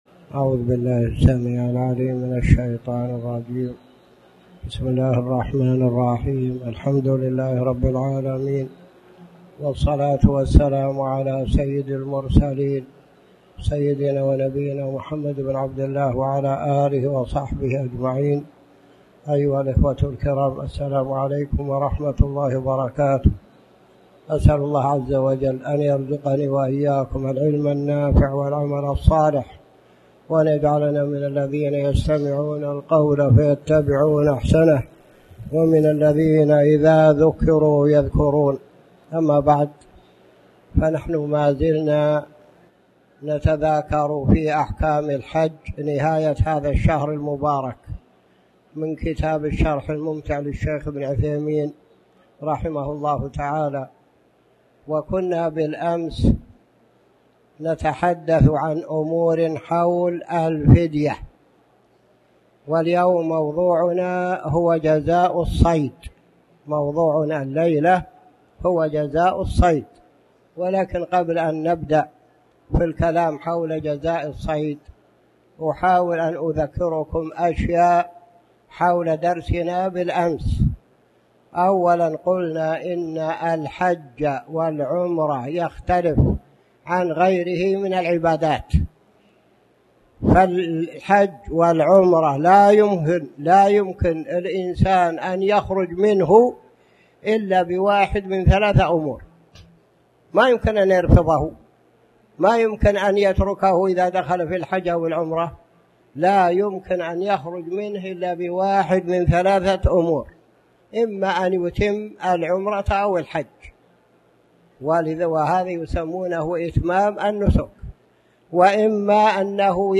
تاريخ النشر ٢٠ ذو الحجة ١٤٣٨ هـ المكان: المسجد الحرام الشيخ